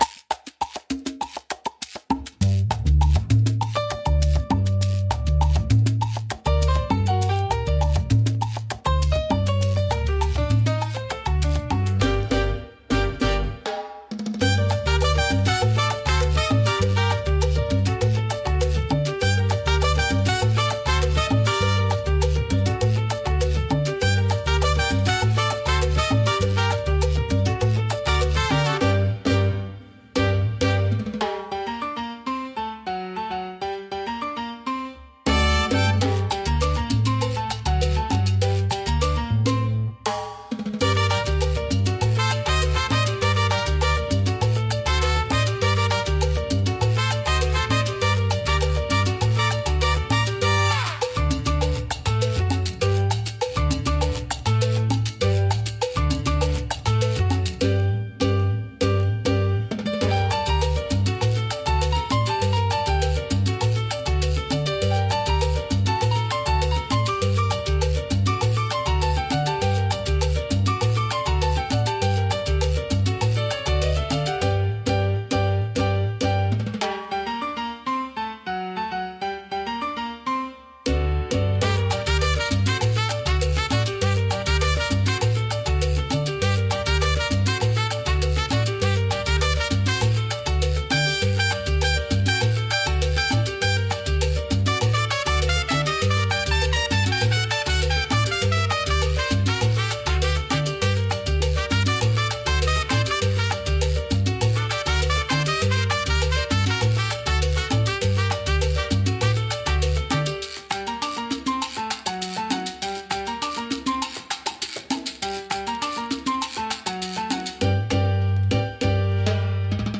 聴く人に活力と情熱を与え、明るく熱気に満ちた空間を作り出します。